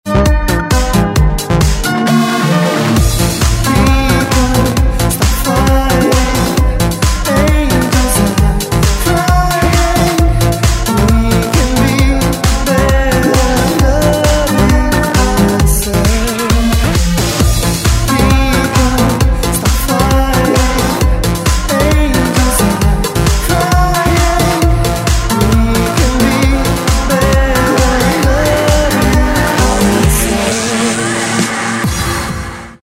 Назад в Мр3 | House
Довольно таки прикольный ремикс друзья! ;)
House Music Top